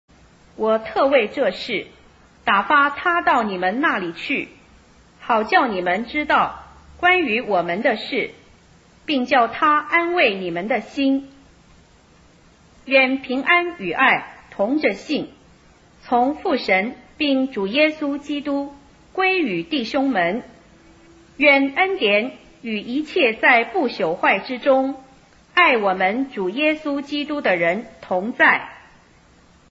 朗讀